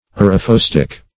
Search Result for " arrhaphostic" : The Collaborative International Dictionary of English v.0.48: Arrhaphostic \Ar`rha*phos"tic\, a. [Gr.
arrhaphostic.mp3